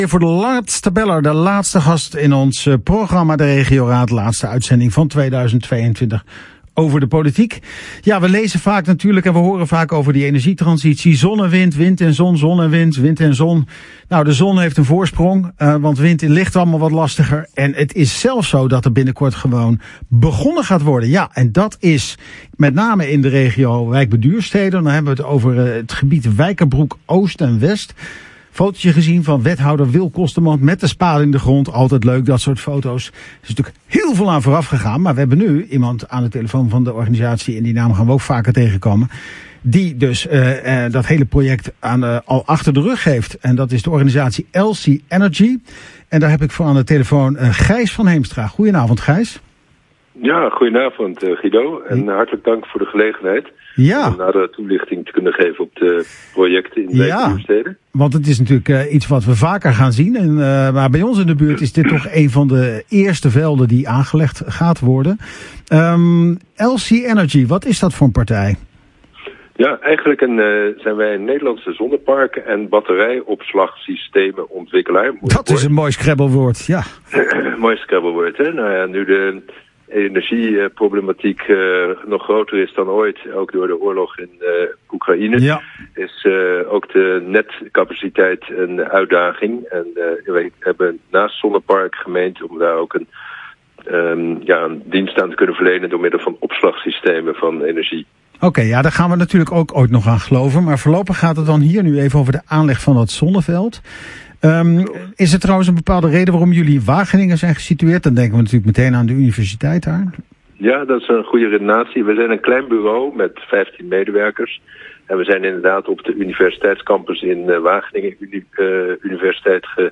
vertelt op de lokale radio meer over de zonnevelden in Het Wijkerbroek.